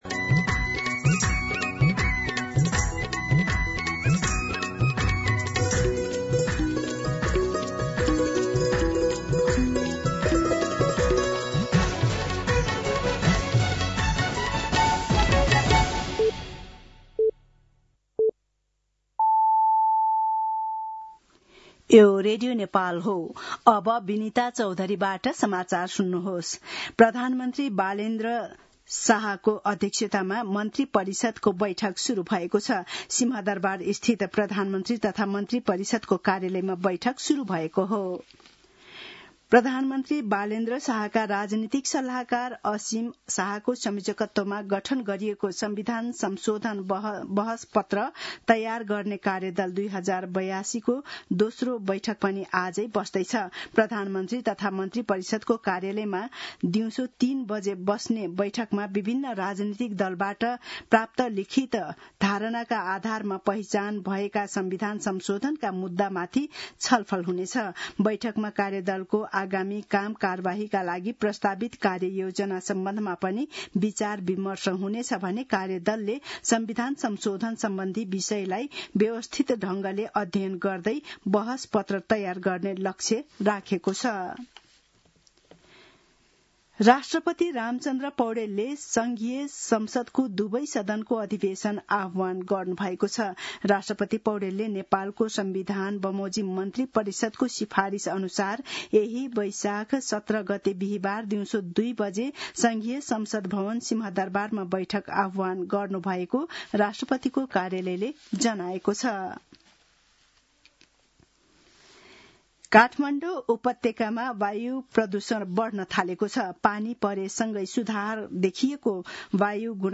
दिउँसो १ बजेको नेपाली समाचार : १० वैशाख , २०८३